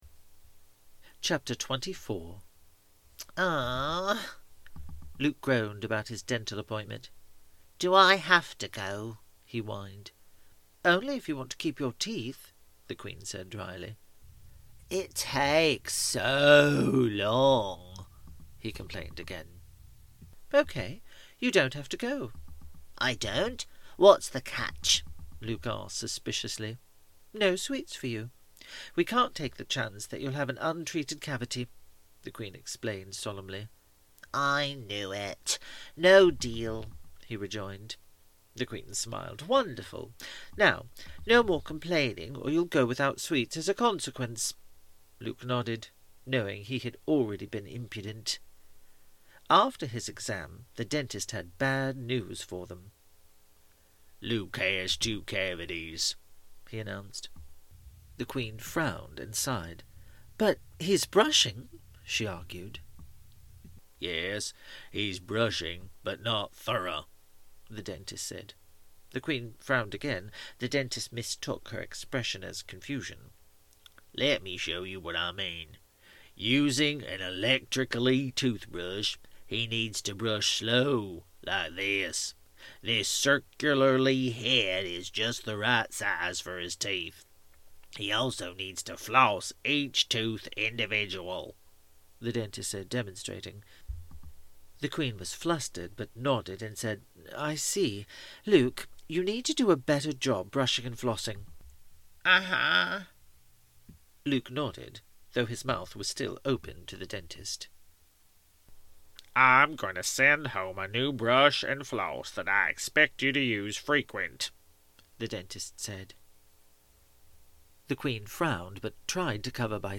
Nova Audiobook
Nova-Chapter-24-Audiobook-sample.mp3